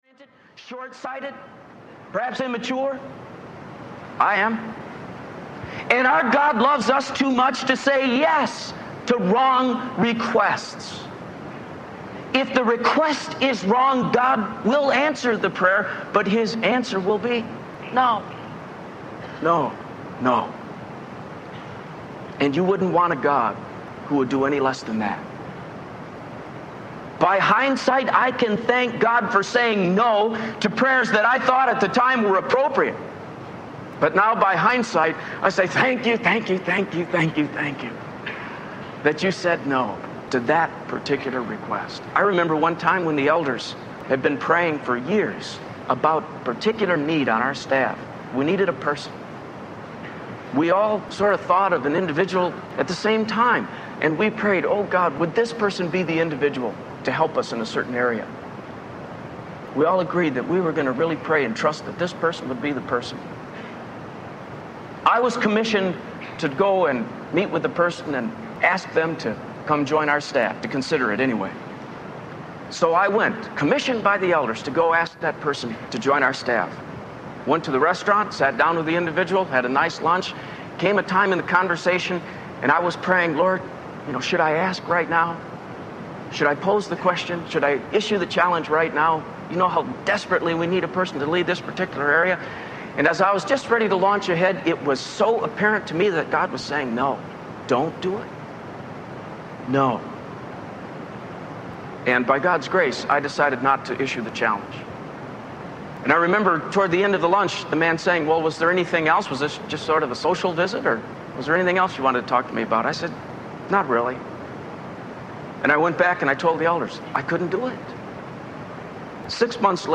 Life Changing Tools for Christians Audiobook